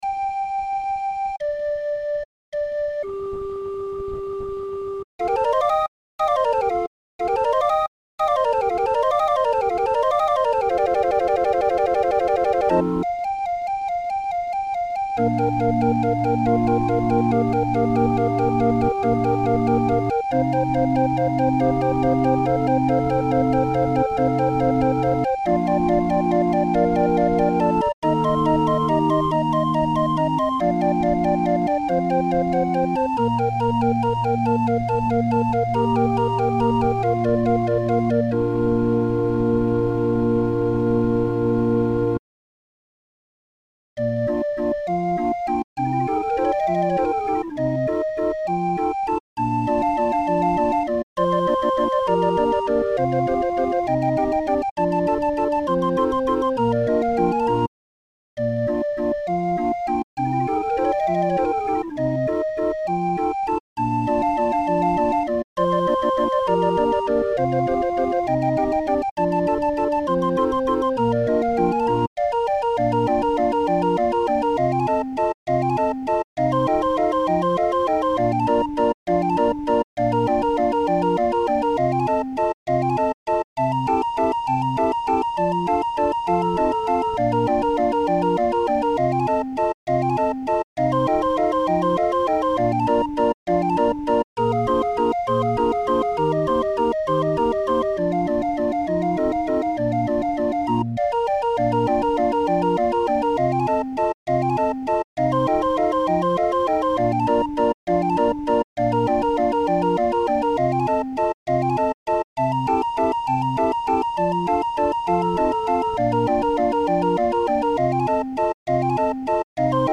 Musikrolle 31-er Raffin